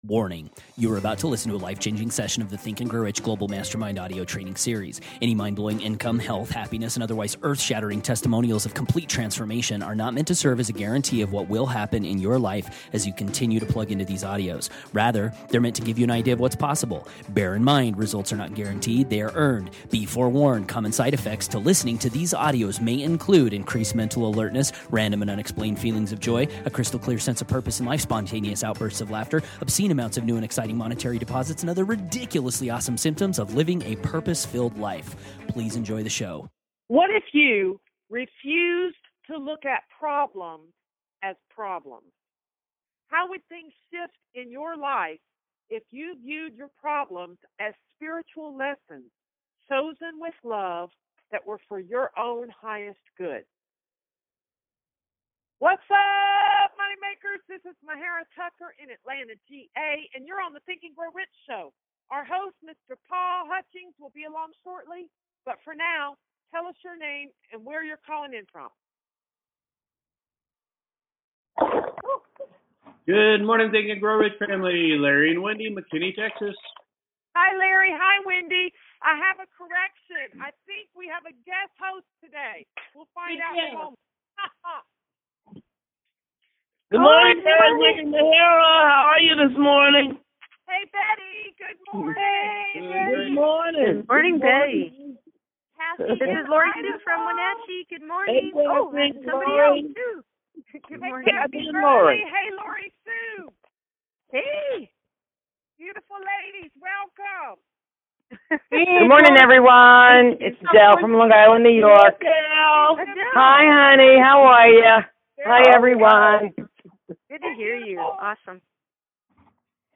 Reading: Think And Grow Rich, Chapter 15, Illusions of Fear… 216 – 223